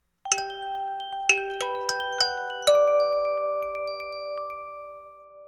PowerOn (alt).ogg